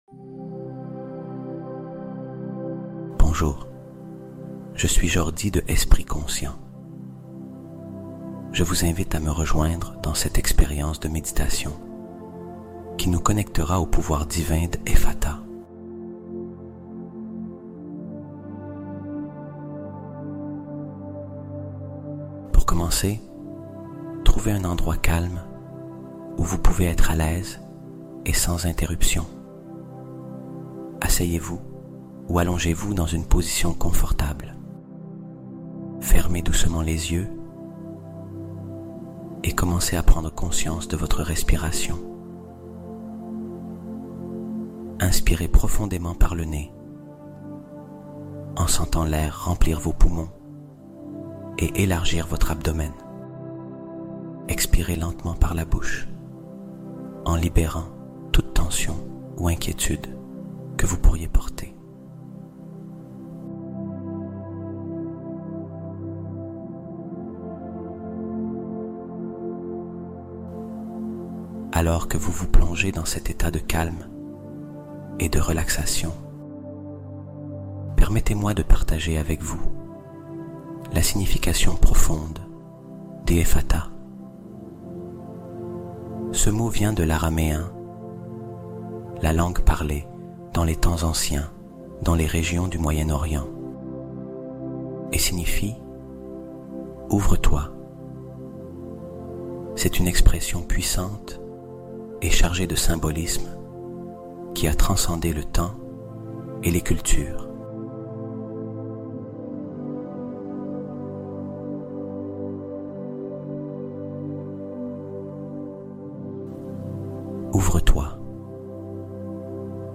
Deviens Mentalement INVINCIBLE en Dormant : Hypnose de Reprogrammation Profonde (Sans Interruption)